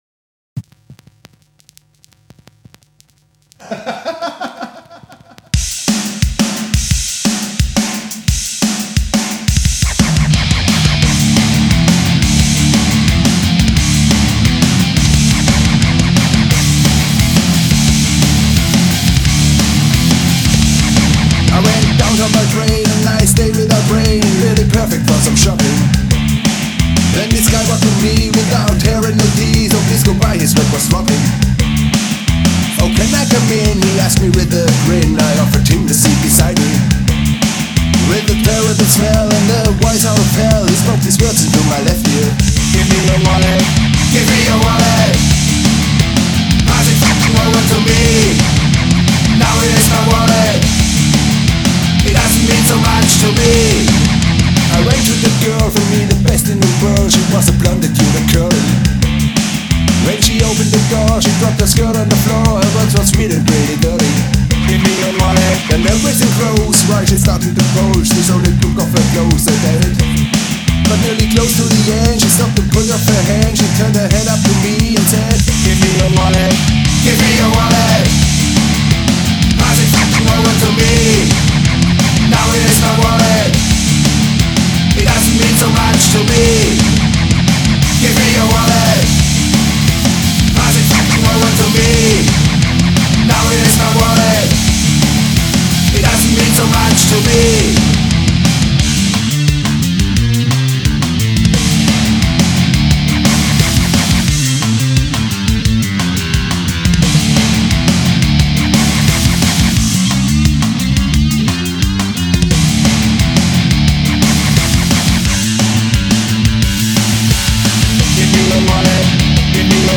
Ich habe mich mit Home-Recording und eigenen Songs beschäftigt.
Gesang
Ich wollte einmal in meinem Leben die Rock-Klischees bedienen: laut und unartig😀 Nehmt es mit einem Grinsen (wie wir zwei auf dem Foto auch) and Bang Your Head!